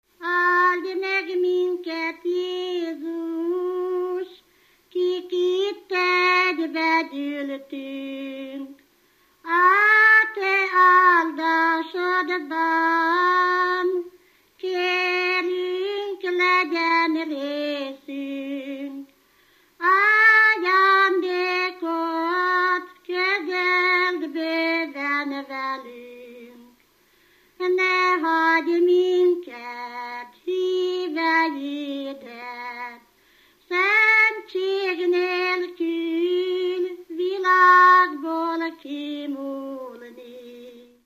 Alföld - Pest-Pilis-Solt-Kiskun vm. - Galgahévíz
ének
Stílus: 4. Sirató stílusú dallamok